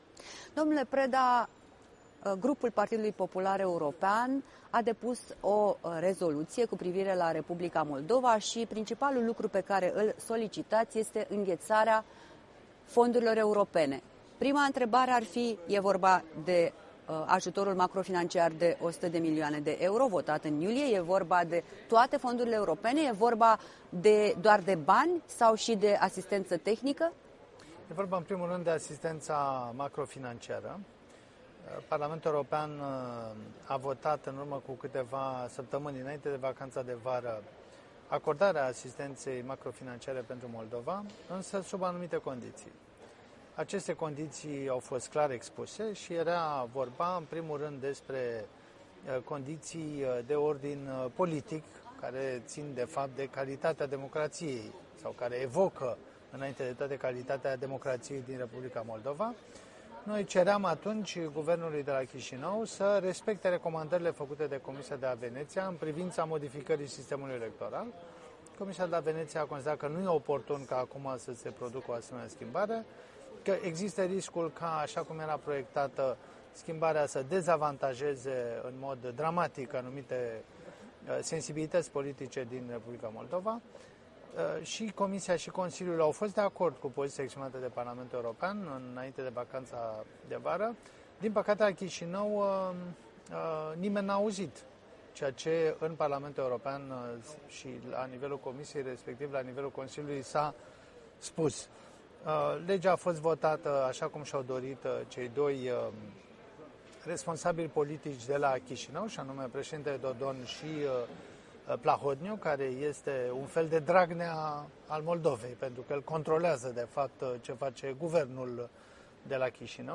Un interviu de la Strasbourg cu europarlamentarul român, membru al Partidului Popular pe tema dezbaterilor din Parlamentul European referitoare la R. Moldova.
În dialog la Strasbourg cu europarlamentarul Cristian Preda